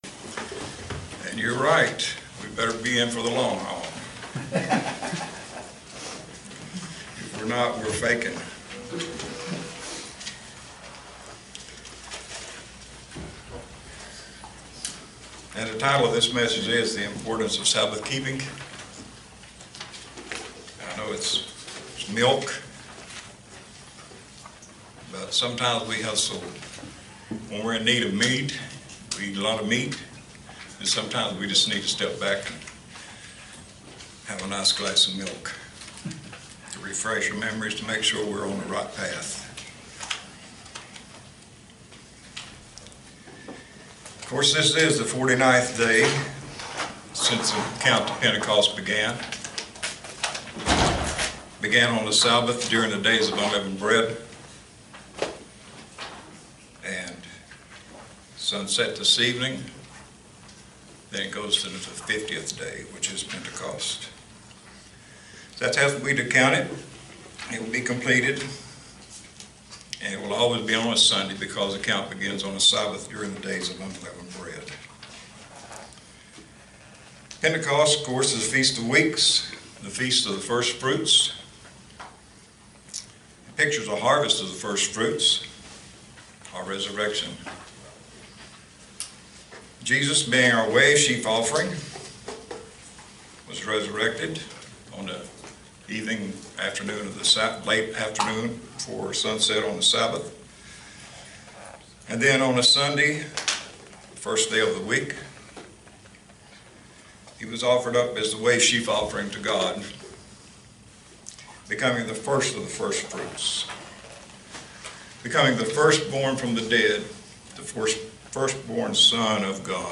Sermons
Given in Roanoke, VA